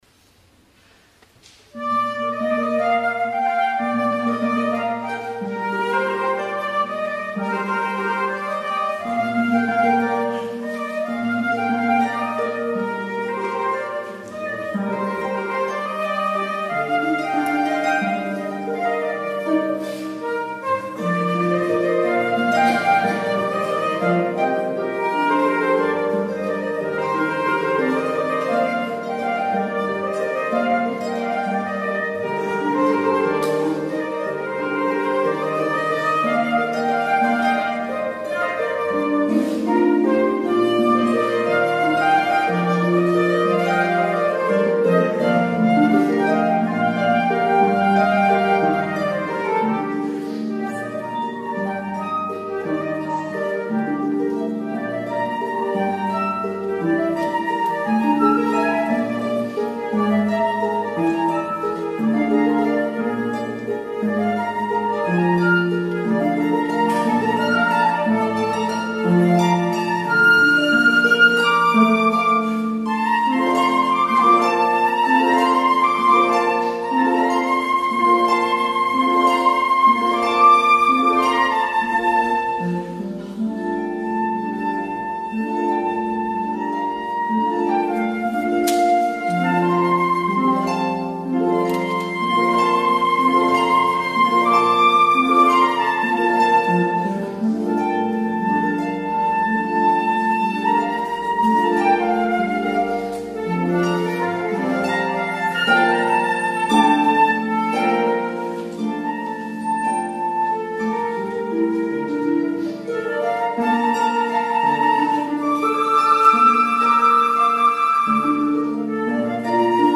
soprano ad lib., flute (or clarinet) and piano (or harp)